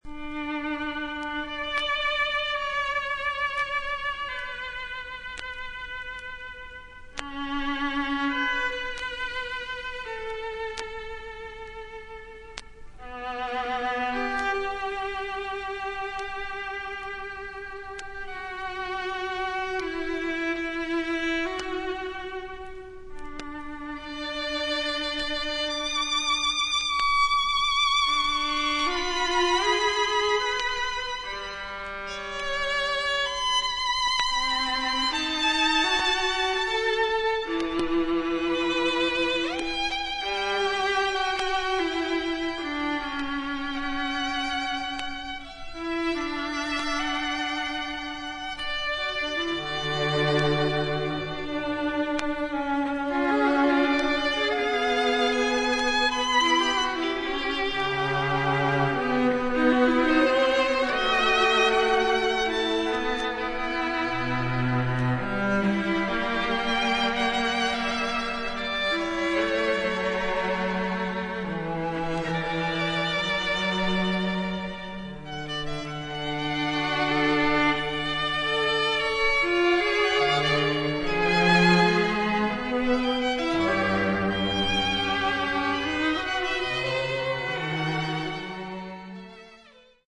MEDIA : VG＋ ※A3の最初に複数回プツッというノイズが入ります。
ダイナミックなオーケストラと自作打楽器により素晴らしいミニマリズムなアンサンブルを披露